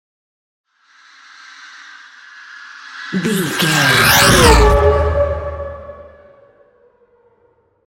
Sci fi vehicle whoosh large
Sound Effects
Atonal
dark
futuristic
intense
whoosh